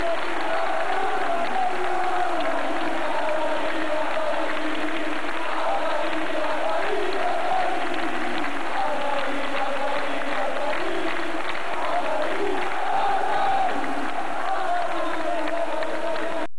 Il coro per Aldair